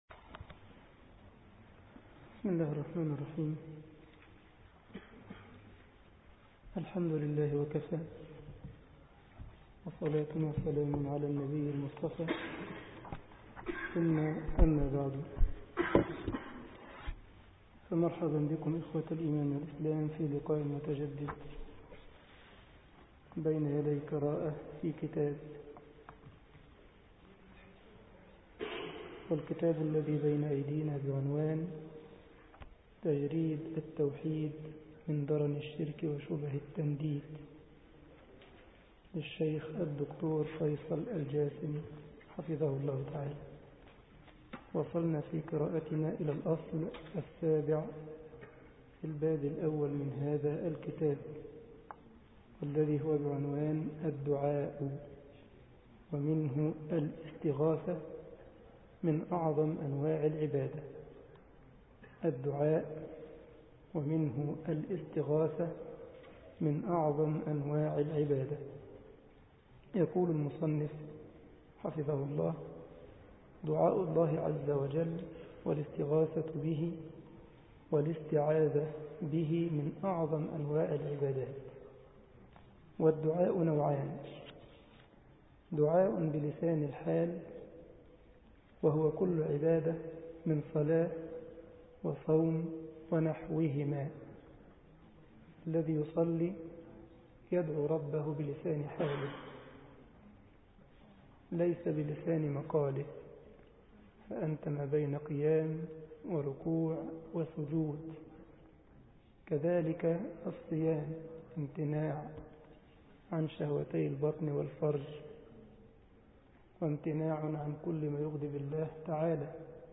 مسجد الجمعية الإسلامية بالسارلند ـ ألمانيا درس 18 رمضان 1433 هـ